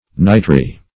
nitry - definition of nitry - synonyms, pronunciation, spelling from Free Dictionary Search Result for " nitry" : The Collaborative International Dictionary of English v.0.48: Nitry \Ni"try\, a. (Chem.)